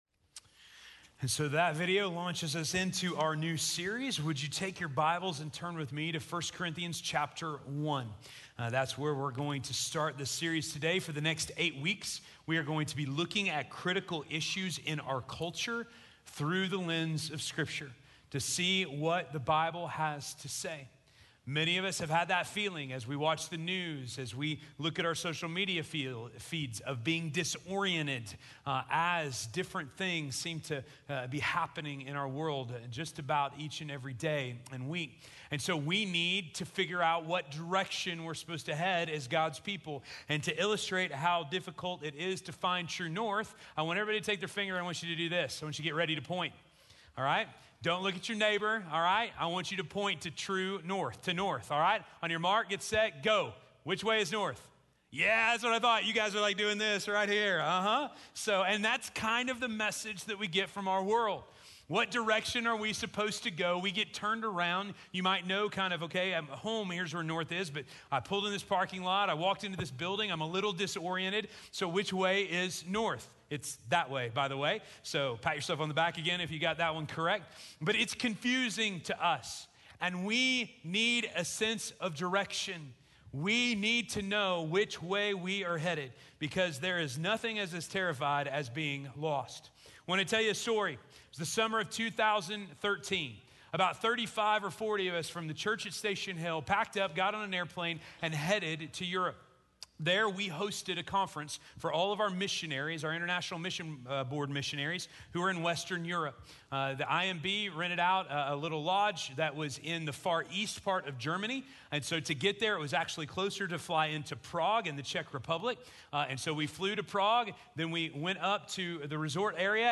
Sermons - Station Hill
Scripture